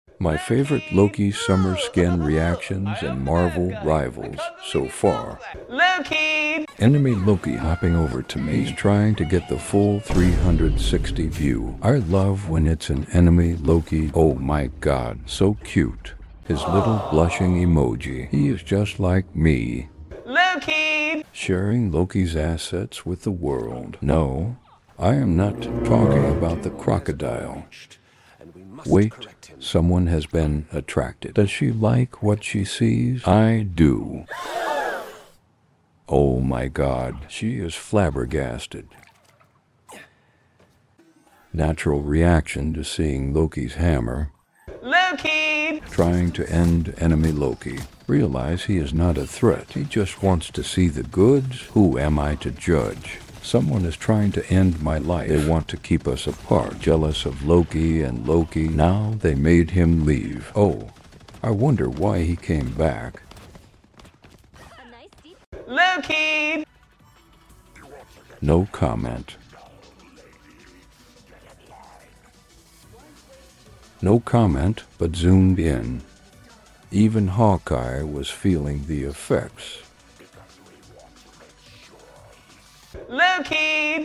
some of my favourite in game sound effects free download
some of my favourite in-game reactions to using the loki summer skin...